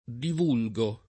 divulgare v.; divulgo [ div 2 l g o ], ‑ghi